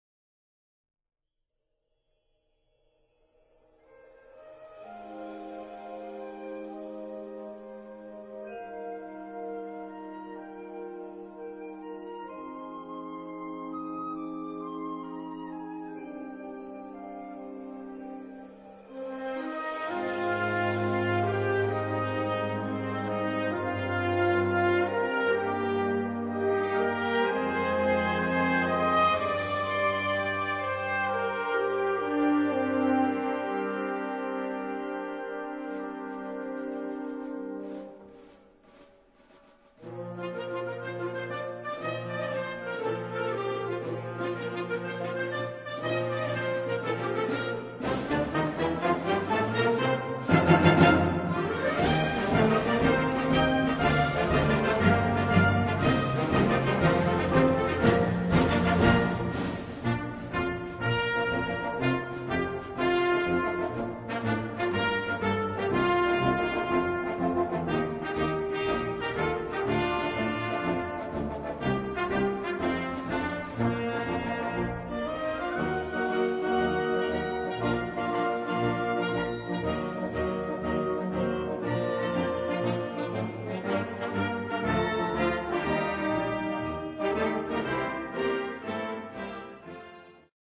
Filmmusik für Blasorchester
Besetzung: Blasorchester